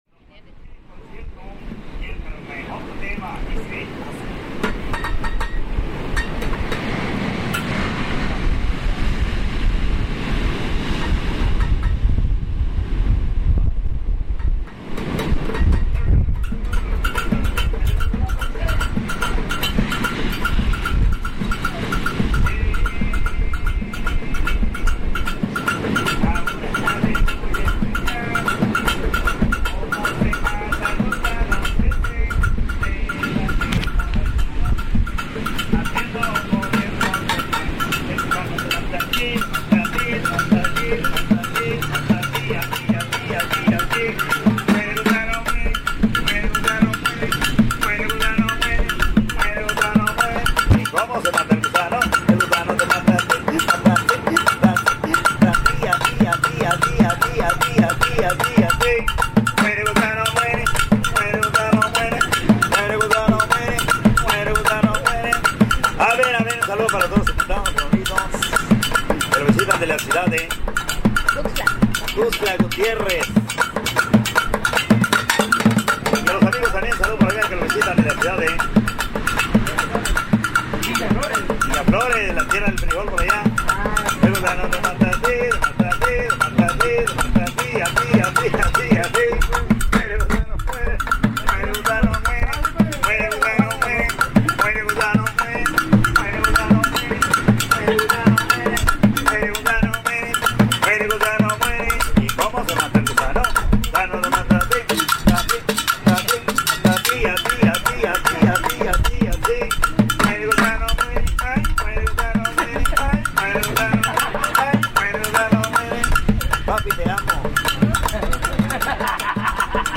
Los invitamos a disfrutar del mar de la Costa de Chiapas, de la música y el entusiasmo de Los Machucachile, originarios de Oaxaca, quienes con objetos cotidianos nos demuestan que para crear no hay límites.
Lugar: Puerto Arista, Tonalá, Chiapas; Mexico.
Equipo: Grabadora Sony ICD-UX80 Stereo